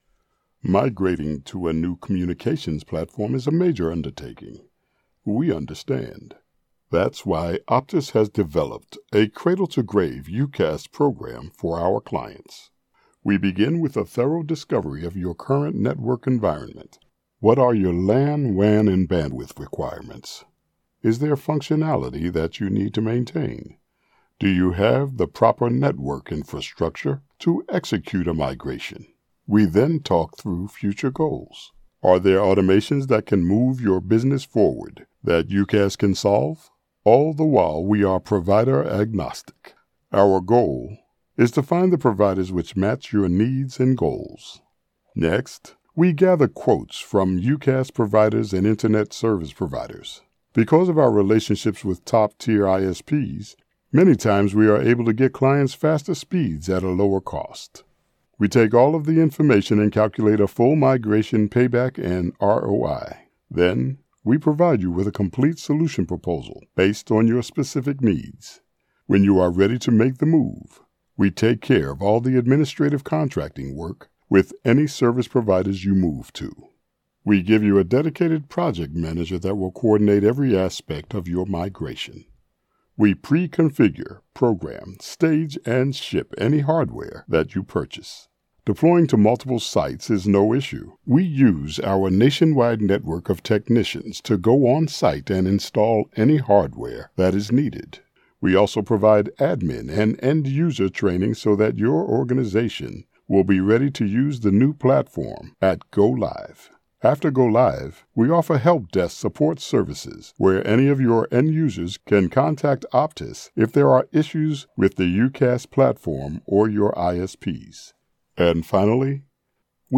Side A of the cassette contained a special message about UCaaS. While we couldn’t book Madonna, we got the next best thing: an Optimus Prime impersonator to deliver the recording.